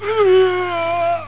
Dying-male.wav